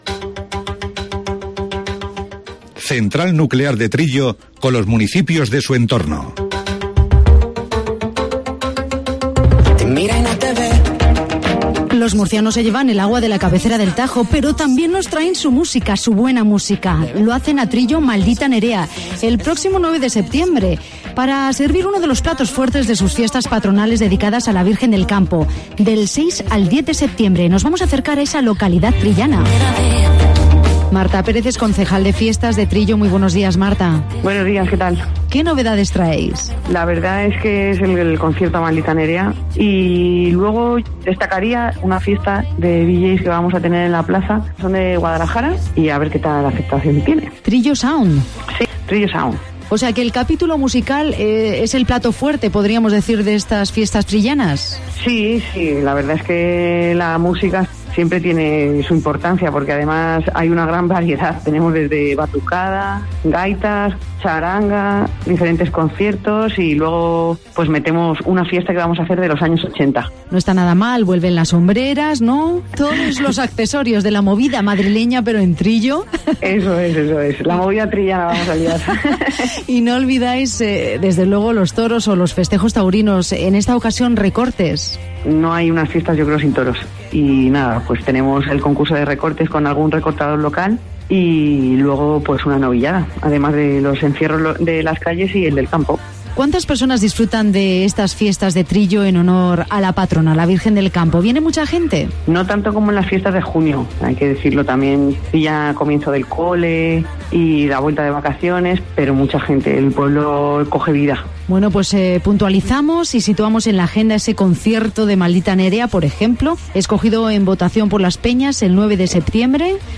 Charlamos con la concejal Marta Pérez.